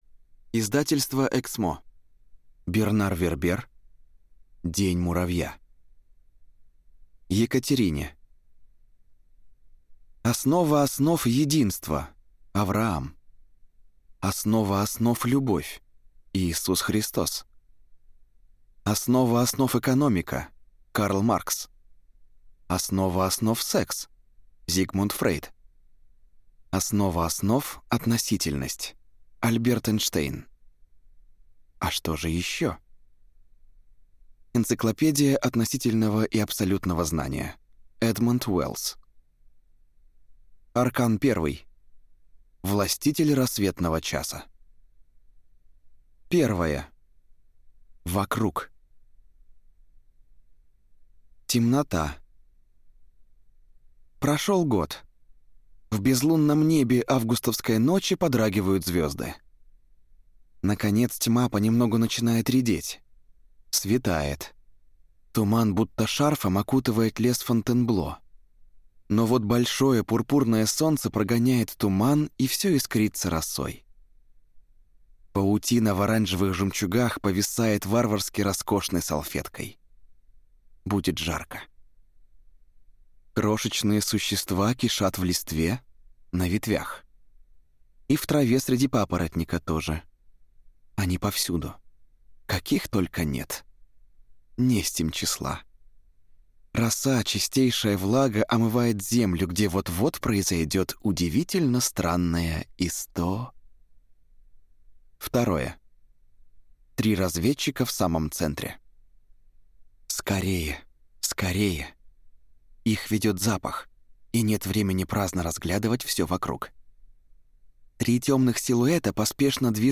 Аудиокнига День муравья | Библиотека аудиокниг